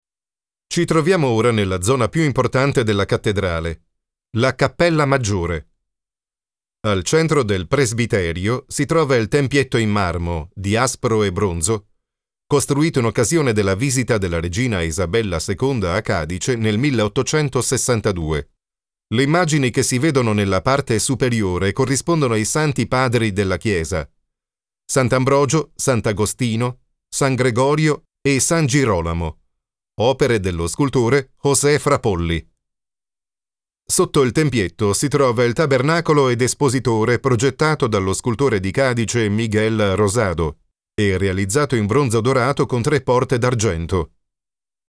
Guias de áudio
Posso descrever minha voz como uma voz masculina de "meia-idade", profunda, calorosa, comunicativa, para documentários ou onde o sentimento é necessário.
Barítono